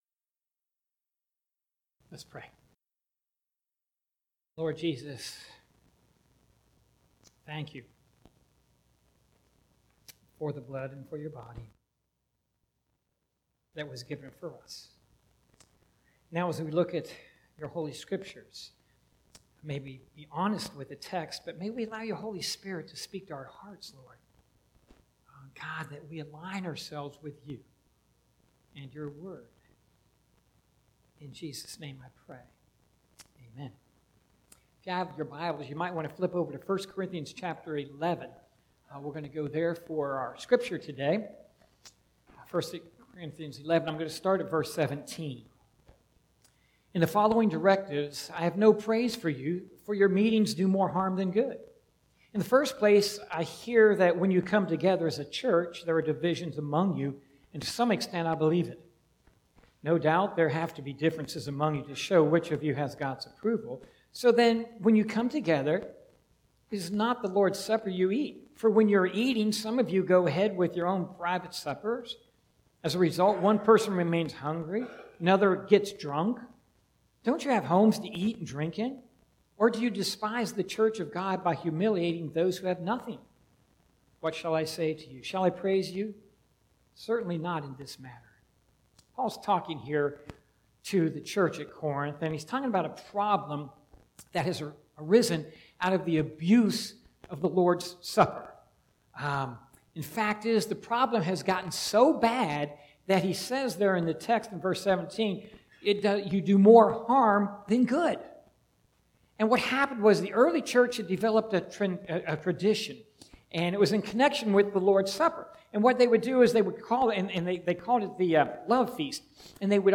Sermons - Parkland Baptist Church